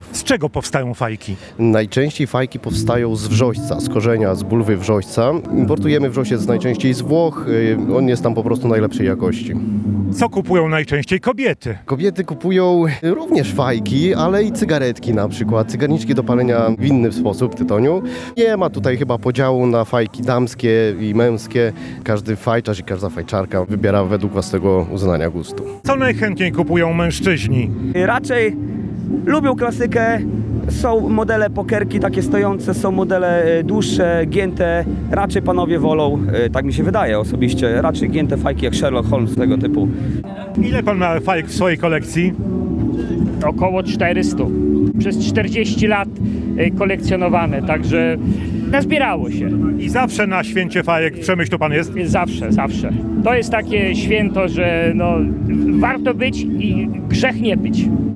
Swieto-Fajki-kiermasz-1.mp3